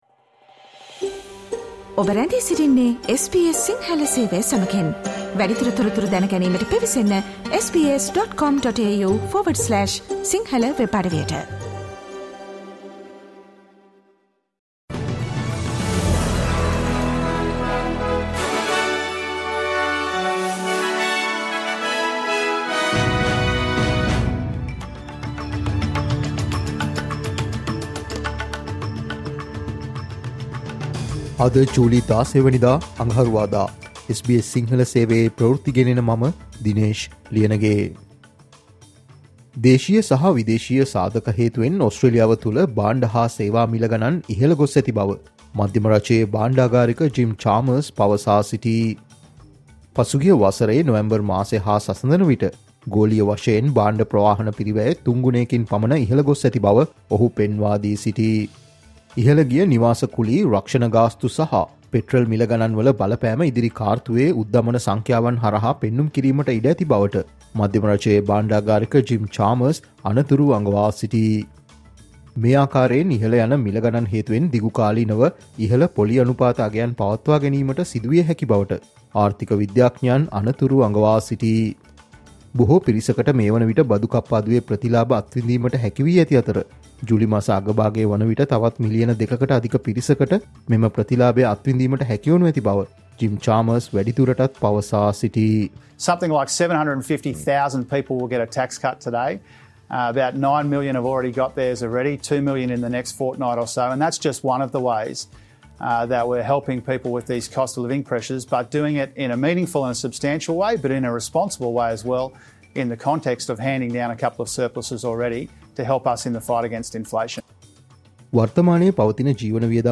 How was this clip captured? Get the latest news of the day - Listen to news flash by SBS Sinhala program on Mondays, Tuesdays, Thursdays and Fridays at 11am Australian time.